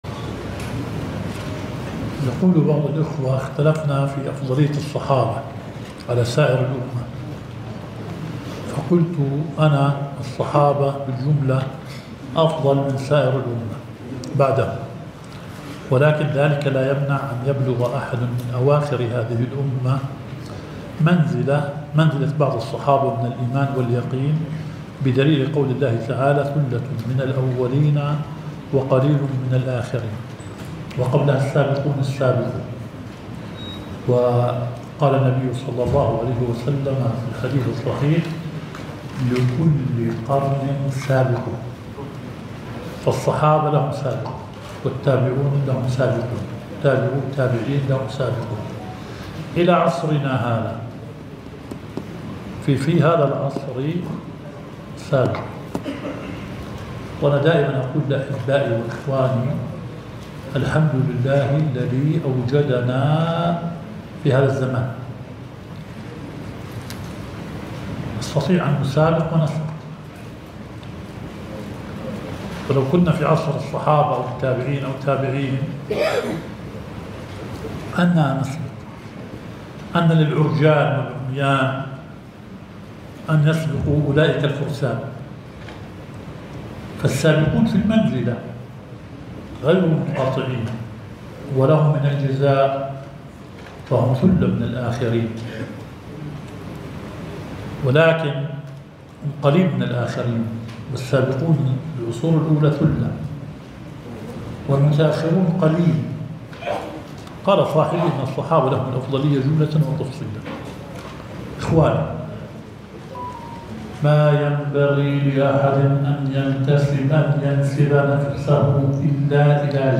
البث المباشر – لدرس شيخنا شرح صحيح مسلم